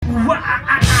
Play, download and share oo wa ah ah ah original sound button!!!!
oo-wa-ah-ah-ah-mp3cut.mp3